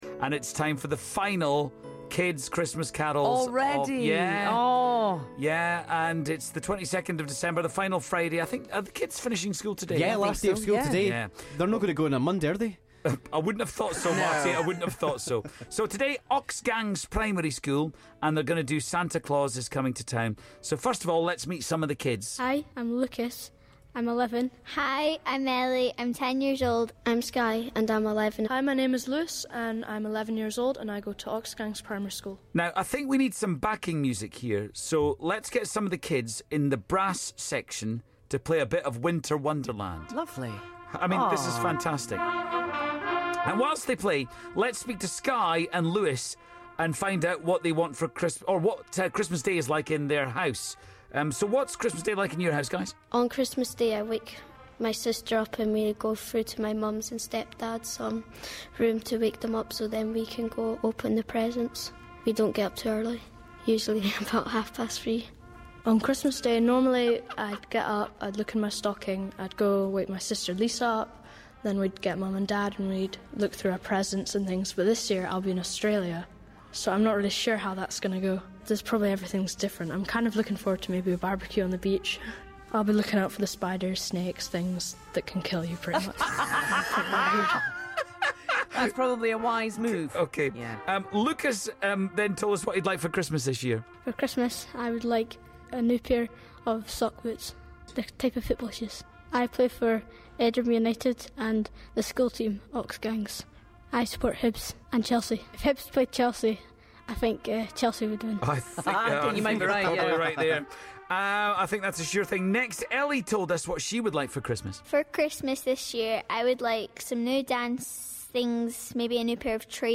The last carol comes from the kids at Oxgangs Primary School in Edinburgh.